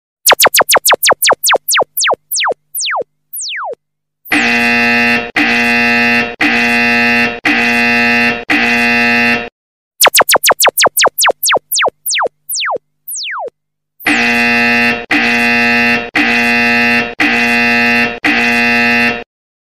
Nada Dering Orderan Masuk Grab dan Gojek Driver Jadi Satu
Kategori: Nada dering
Keterangan: Bunyi khas tembak laser ini bikin kamu langsung siaga pas ada pesanan masuk. Cocok buat kamu yang ojek online, biar nggak ketinggalan order.